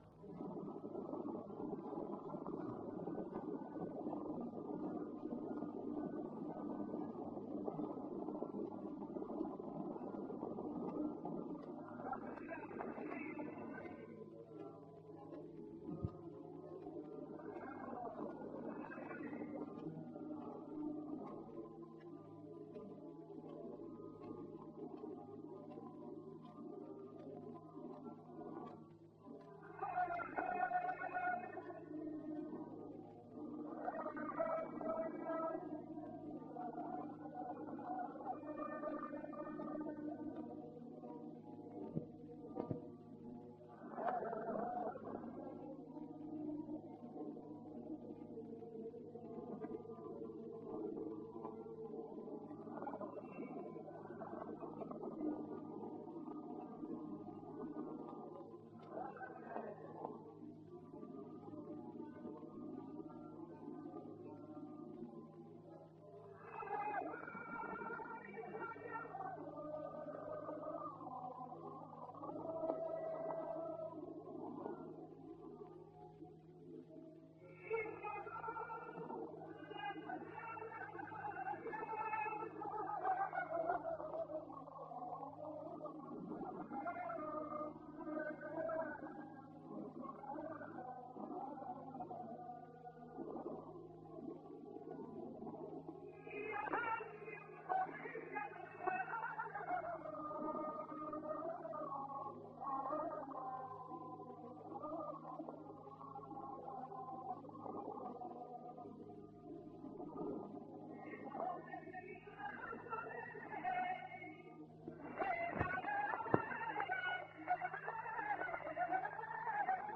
Surtout, son style vocal singulier rend ses interprétations fondamentalement originales, quel que soit le modèle de référence : sur le plan mélodique, un usage intensif des notes de passage chromatiques, souvent accompagnées de portamentos ; sur le plan ornemental, un continuum vibrato élargi/mélismes, le passage de l’un aux autres étant souvent indiscernable — de plus, contrairement à l’usage, ses mélismes plongent fréquemment sous la note porteuse, et procèdent parfois par notes disjointes, tempérées ou non.
NB : levanticas n°4 à 6 — guitare : Ramón Montoya.
levantica_5_del_cojo_1924.mp3